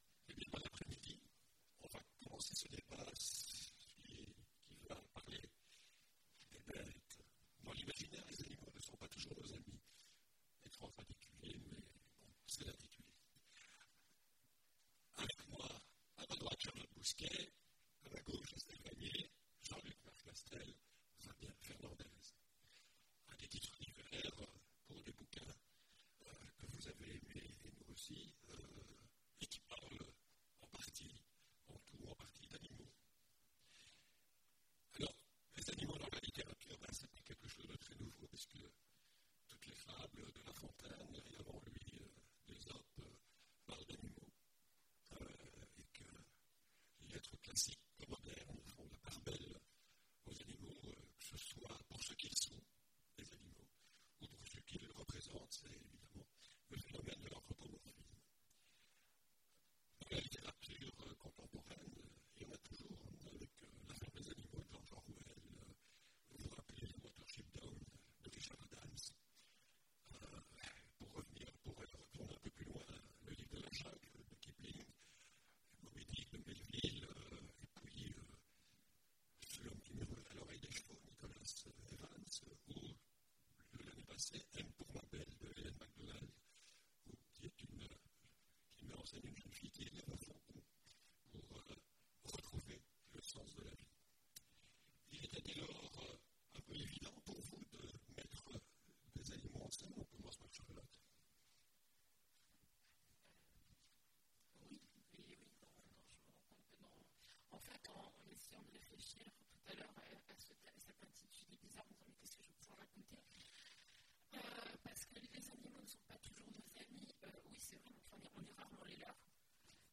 Imaginales 2017 : Conférence Dans l'imaginaire, les animaux... ne sont pas toujours nos amis !